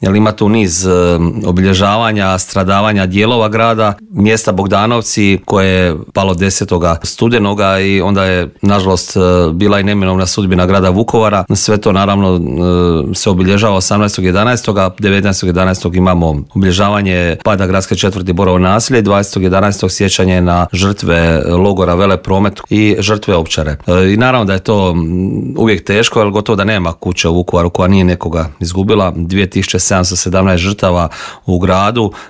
Značaj ovog dana vrlo dobro zna saborski zastupnik i predsjednik Hrvatskih suverenista Marijan Pavliček koji je u studiju Media servisa istaknuo da je cijeli mjesec izrazito emotivan za Vukovarce: